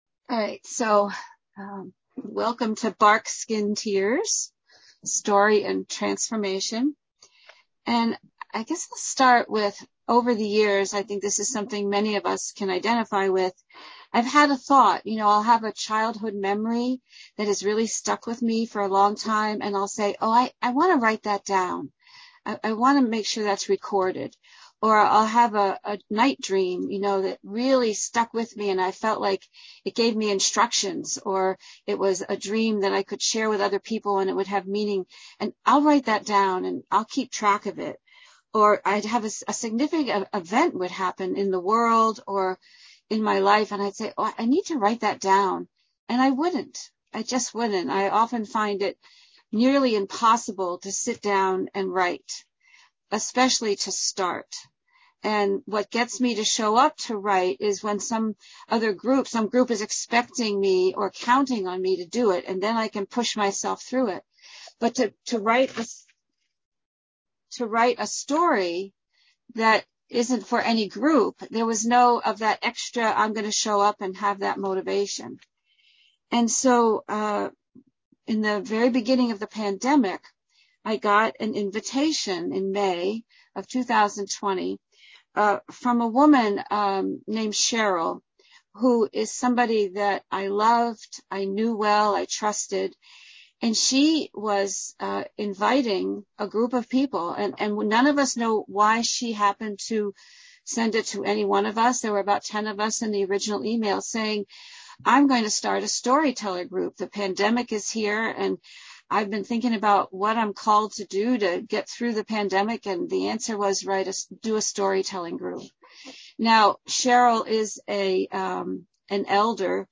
Each week the day begins with a short lecture from one of participants on how the day's issue relates to her personal life.